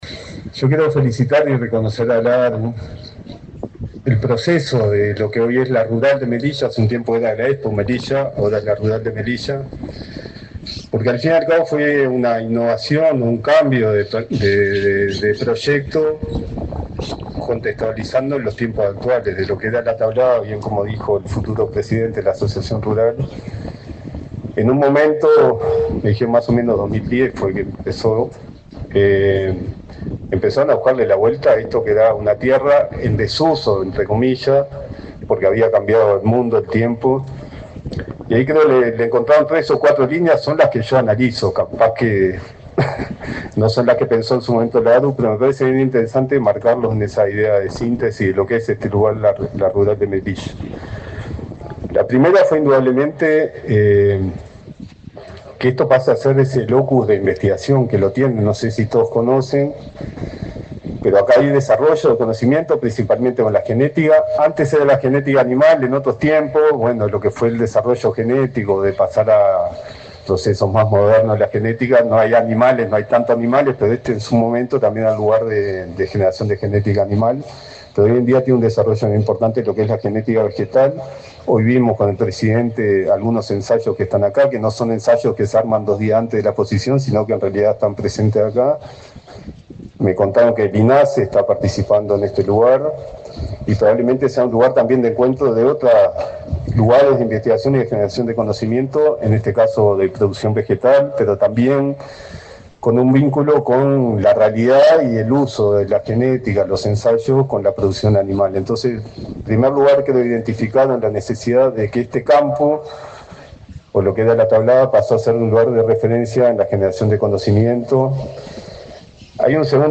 Palabras del subsecretario de Ganadería, Matías Carámbula
El subsecretario de Ganadería, Agricultura y Pesca, Matías Carámbula, se expresó, este jueves 3, durante la apertura de la exposición Rural de Melilla